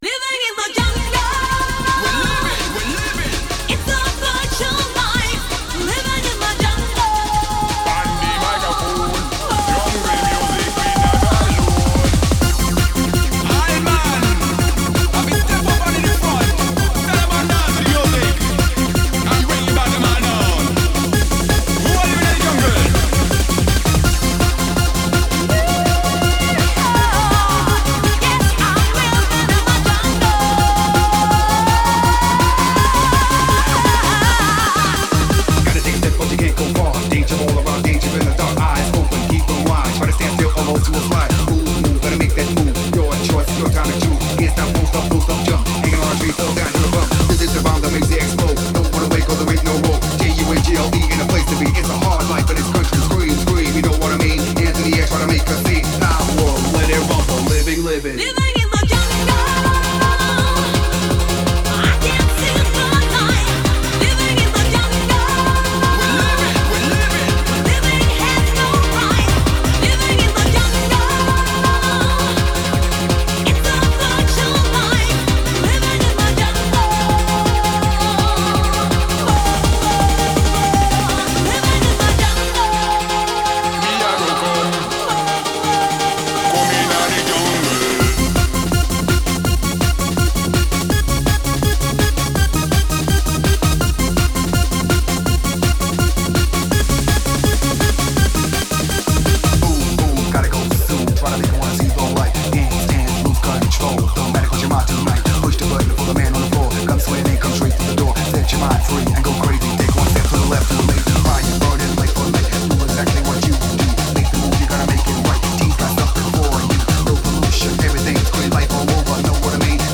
Genre: Happy Hardcore.